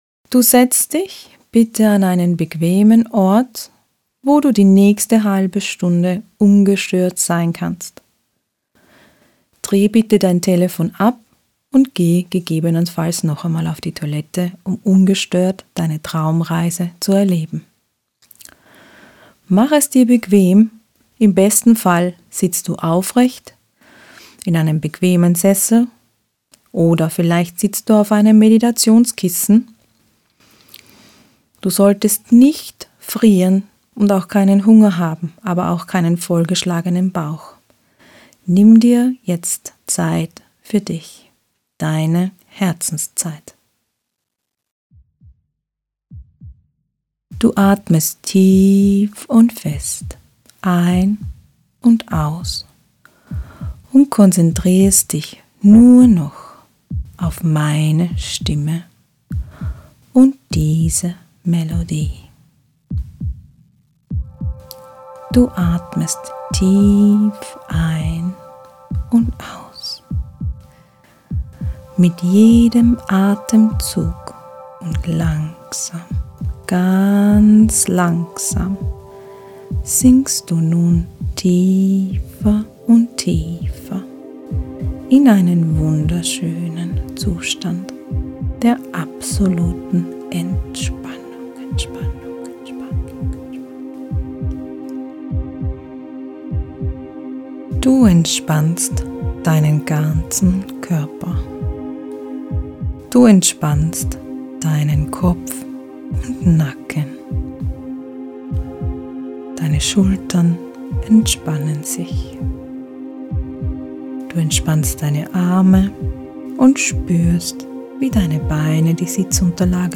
traumreise-inneres-kind.mp3